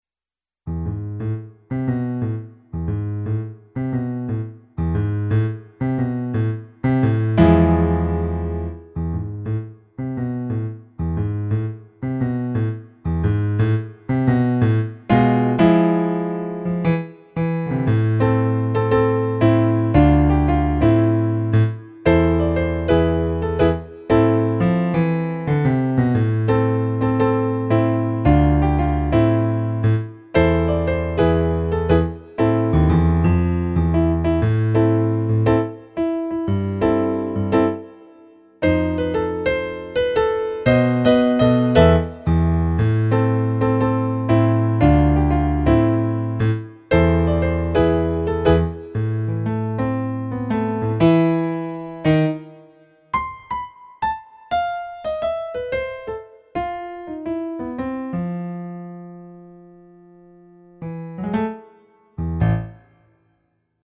contains eight piano solo arrangements.
spy remix